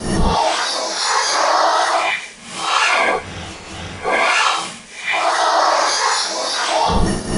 File:LM Early Ghost Screeching Sound.oga
LM_Early_Ghost_Screeching_Sound.oga.mp3